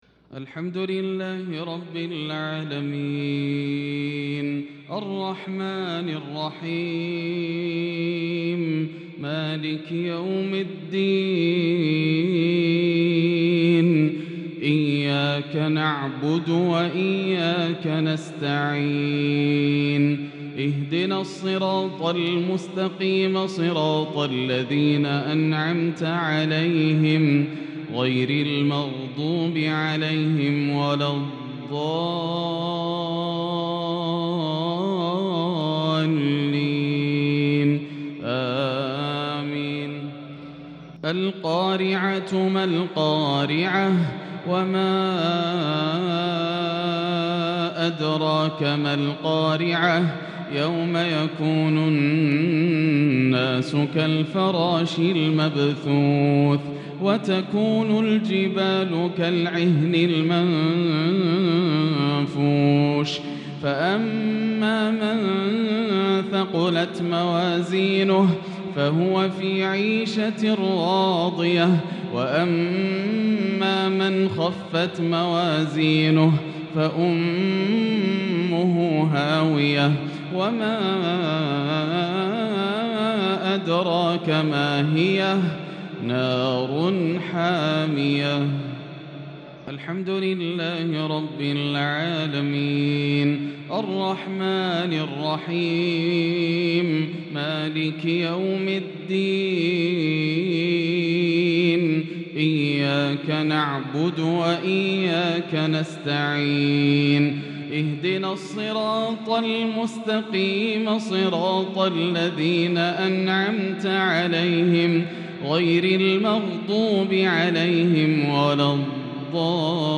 مغرب الأحد 6-6-1443هـ سورتي القارعة و التكاثر |Maghrib prayer from Surat AlQari'ah and al-Takathur 9-1-2022 > 1443 🕋 > الفروض - تلاوات الحرمين